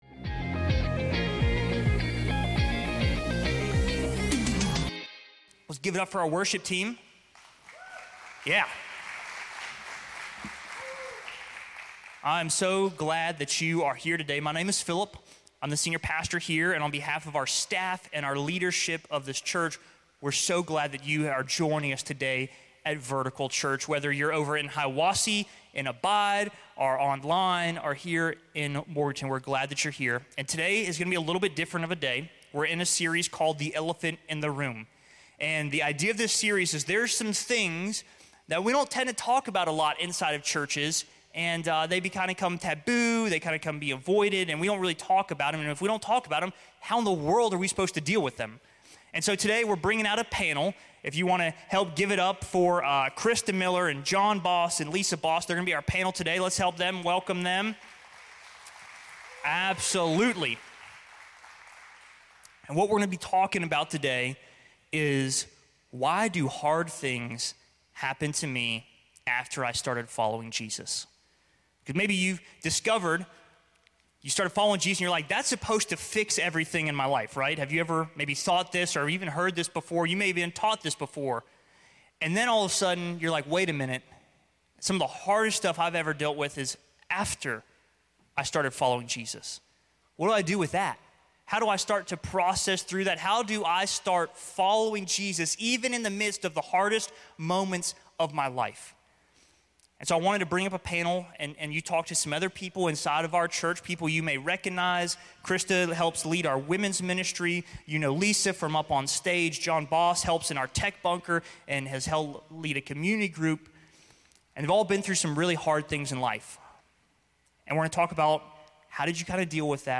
It can be easy to start to question, if I'm following Jesus why is all this happening to me? In this message we have a panel of people who open up about hard things they have been through, and talk about how they were able to follow Jesus through it all.